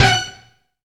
STRING HIT 4.wav